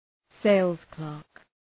{‘seılz,klɜ:rk} (Ουσιαστικό) ● πωλητής